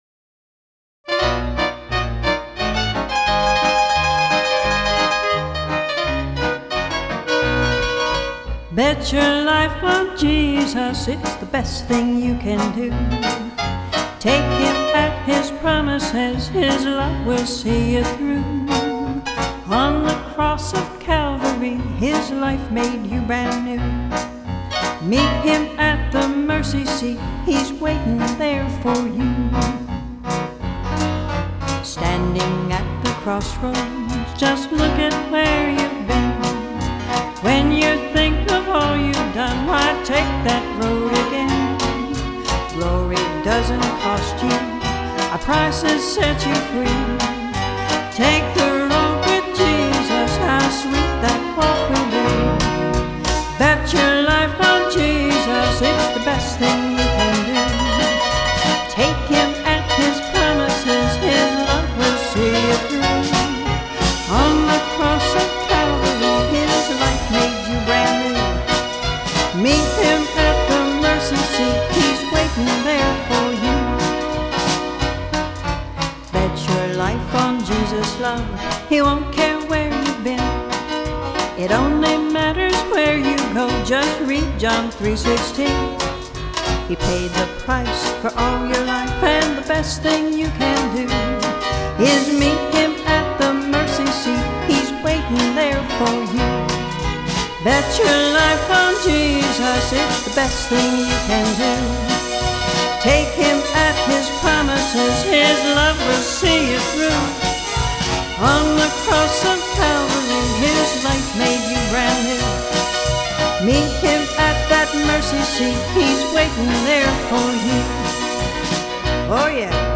Recorded at Springwood Studios, Palmyra, PA - Copyright 2004